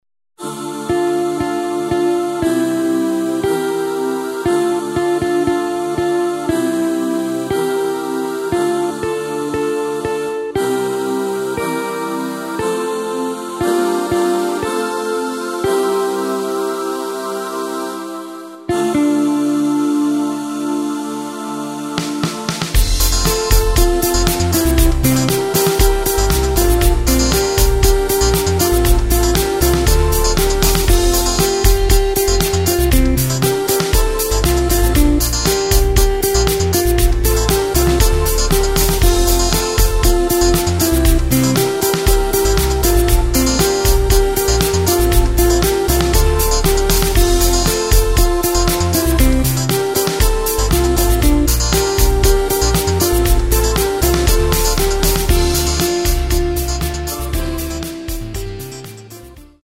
Takt:          4/4
Tempo:         118.00
Tonart:            F